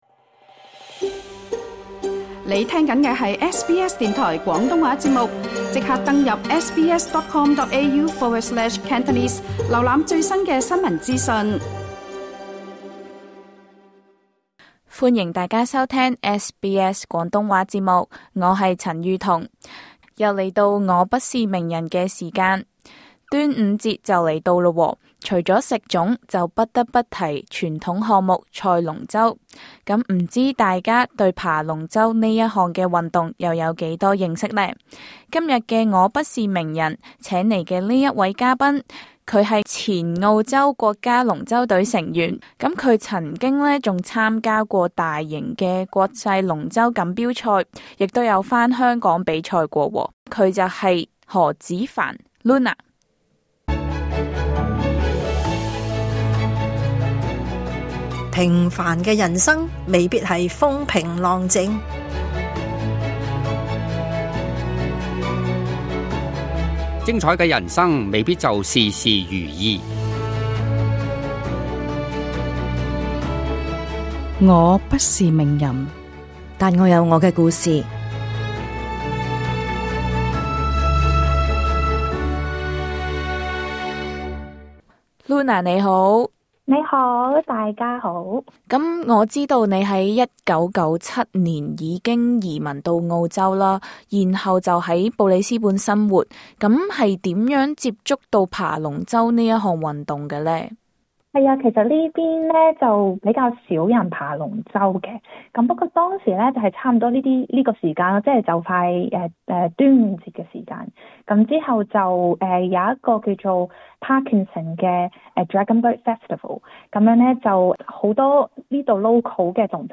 想了解更多詳情，請聽【我不是名人】的足本訪問。